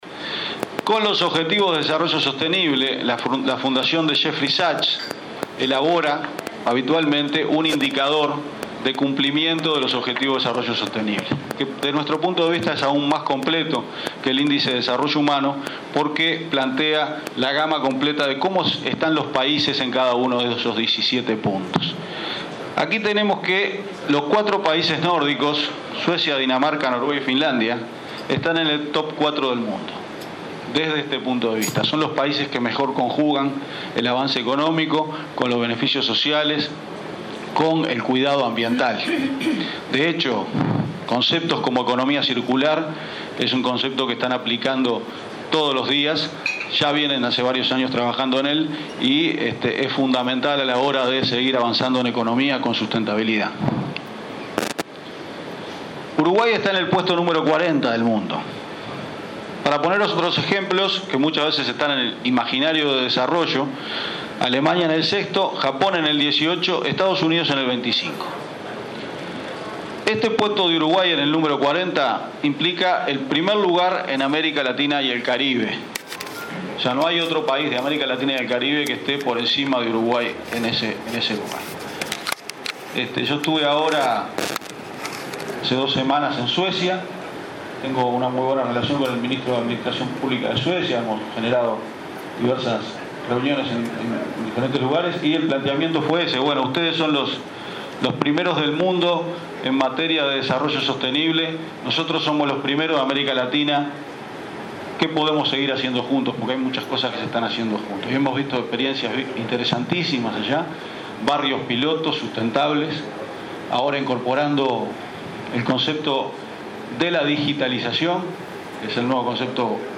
El Director de OPP, Alvaro García, destacó que Uruguay, primero en América Latina en desarrollo sostenible, debe vincularse con los países que lideran en esta temática en el mundo, Suecia, Dinamarca y Finlandia, para conjugar el avance económico con los beneficios sociales y el cuidado ambiental. En un evento de ACDE, habló del sitio web Uruguay Suma Valor, donde las empresas difunden sus proyectos alineados con los ODS.